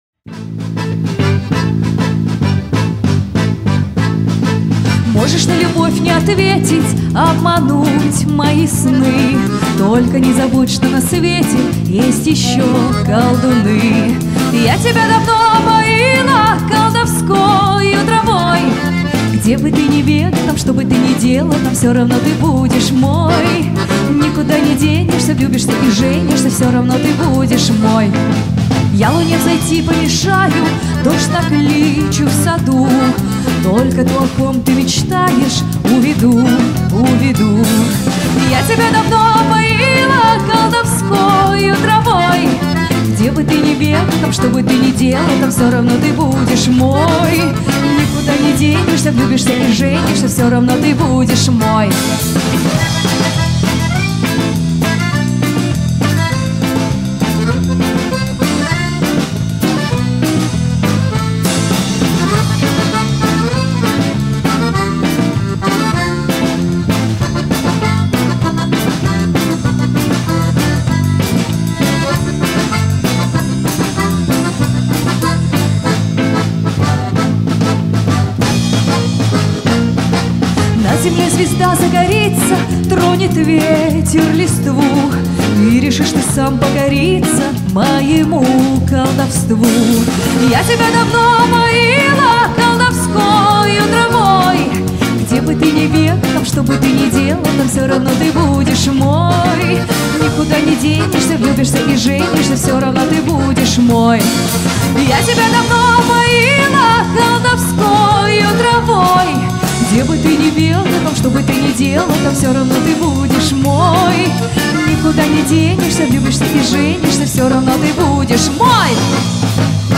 исполняет шлягеры советской эстрады 50-70х. годов.
аккордеон
бас гитара
барабаны